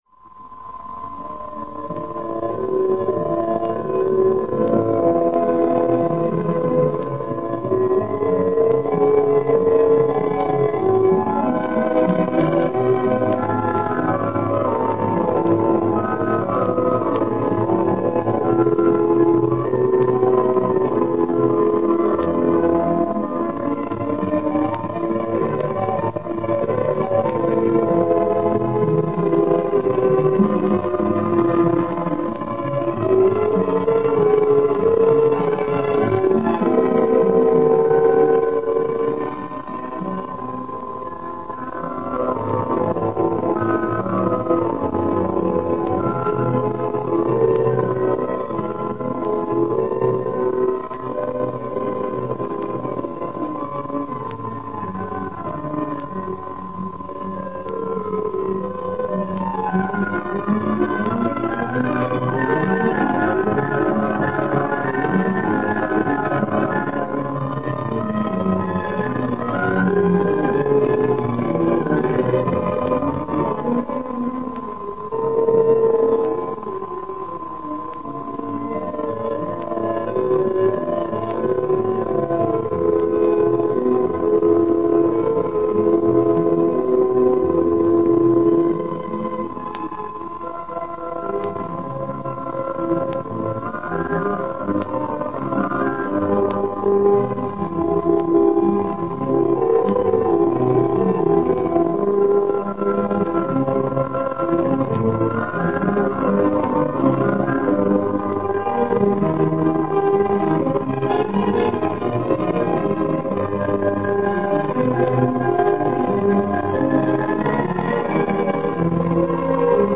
Zene